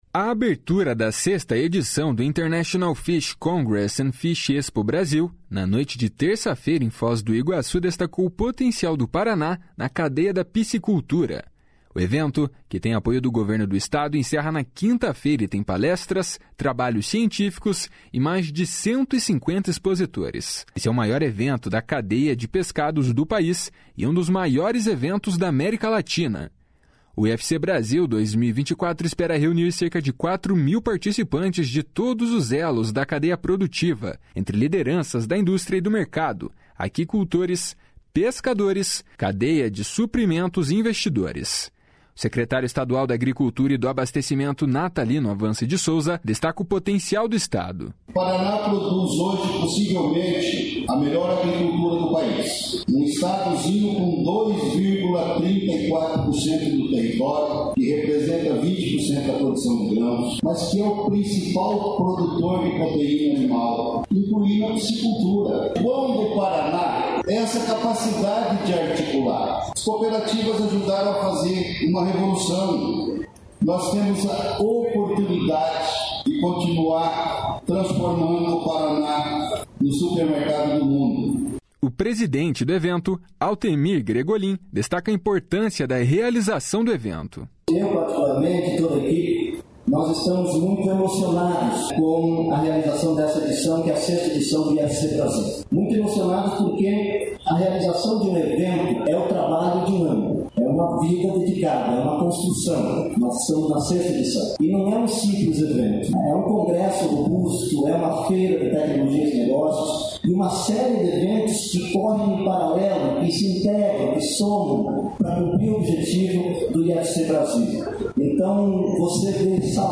O secretário estadual da Agricultura e do Abastecimento, Natalino Avance de Souza, destaca o potencial do Estado.